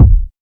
KICK.138.NEPT.wav